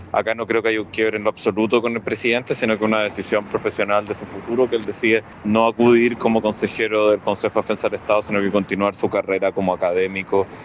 En ese contexto, el senador socialista Tomás de Rementería afirmó que Cordero habría sido un gran aporte para el CDE, pero sostuvo que su decisión se explica por un camino profesional y no por un quiebre con el presidente.